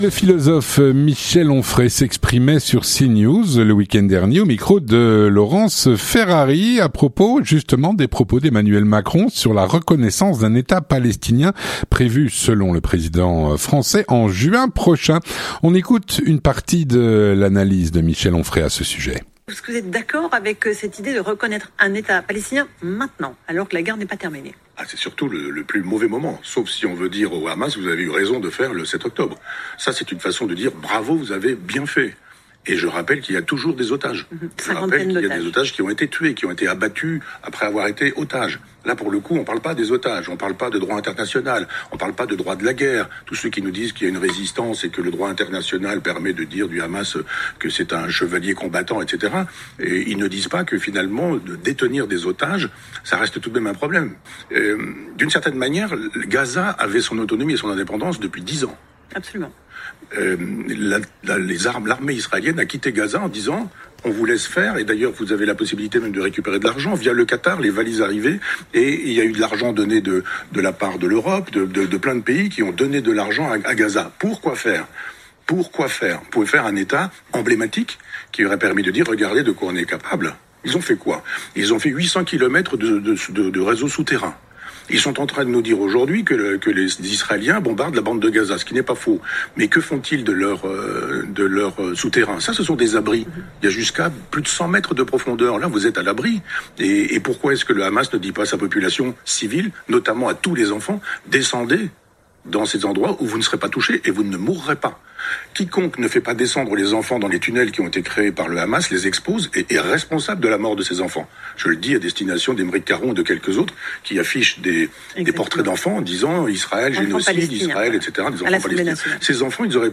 Le philosophe Michel Onfray s’exprimait sur Cnews ce week-end. Il revenait ainsi, au micro de Laurence Ferrari, sur les propos d’Emmanuel Macron quant à la reconnaissance d’un état palestinien, par la France, au mois de  juin prochain.
On écoute une partie de son analyse.